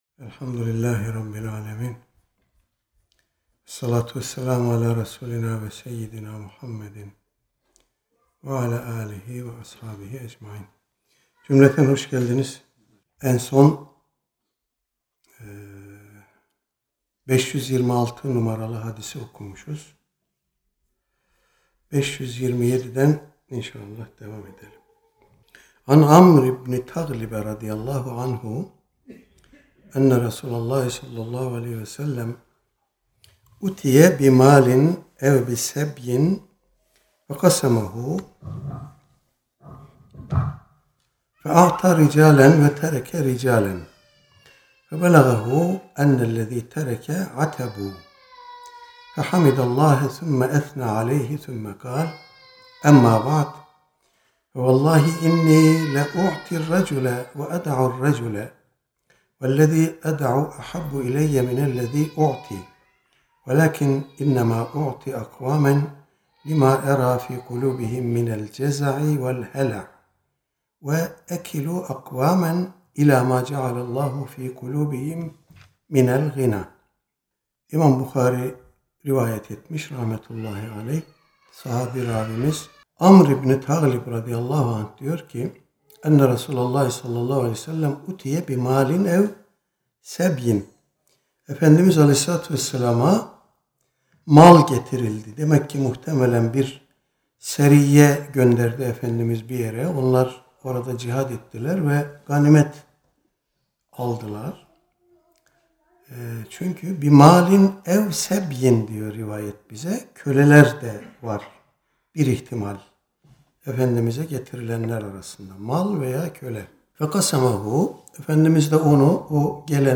Her hafta mutat olarak icra edilen, Riyâzu’s-Sâlihin seminerimizde bu hafta, “Kanaat ve Tokgözlülük” konusu ele alındı.
Seminer yeri: Uluçınar Vakfı – Pendik.